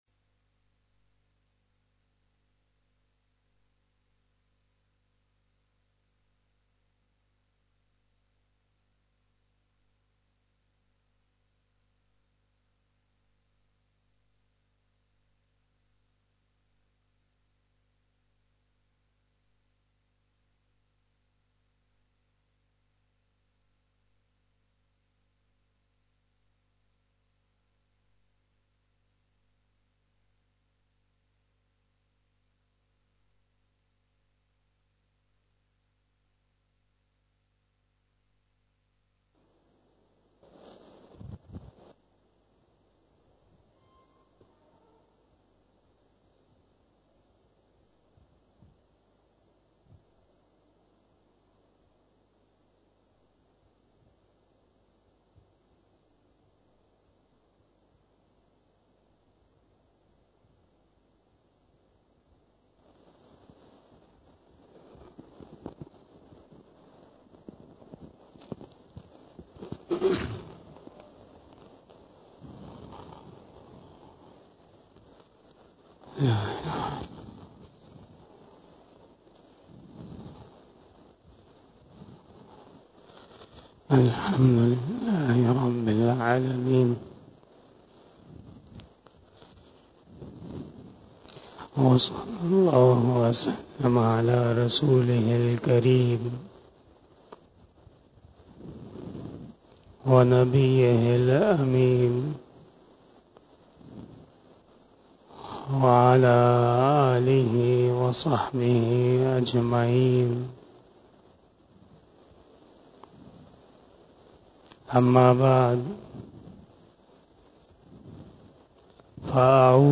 26 BAYAN E JUMMAH 30 June 2023 (11 Zul Hajjah 1444HJ)
02:16 PM 226 Khitab-e-Jummah 2023 --